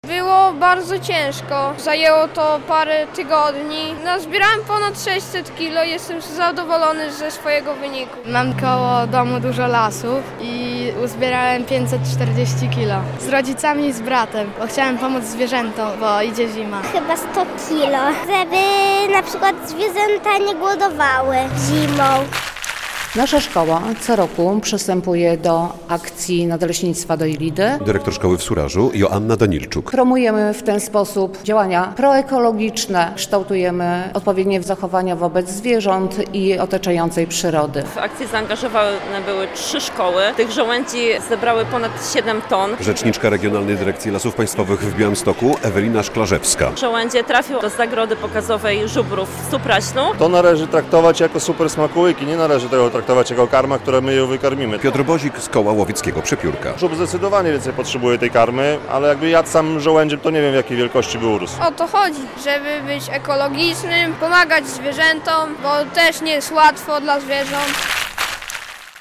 Finał akcji zbierania żołędzi w Surażu, 15.11.2024, fot.
relacja